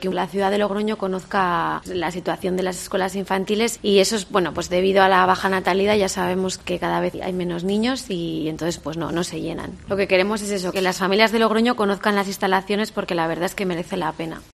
Se ha publicado un folleto explicativo para promocionar las cuatro Escuelas Infantiles y que se cubran todas las plazas, como ha señalado Beatriz Nalda, concejala de Educación y Juventud.